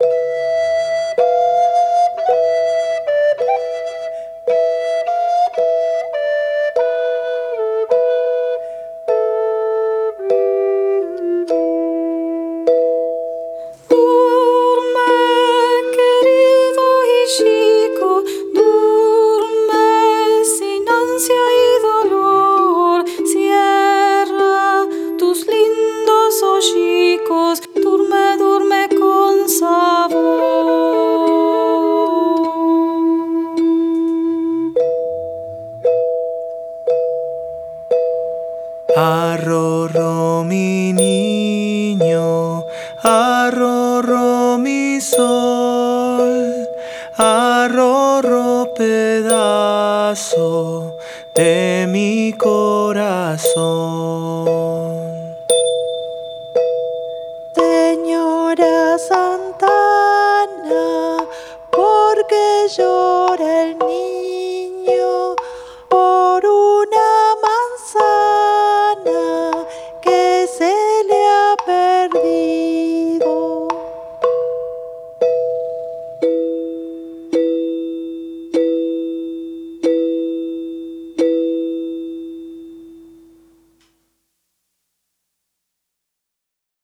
Canciones de cuna en ladino  (sefaradí) y en castellano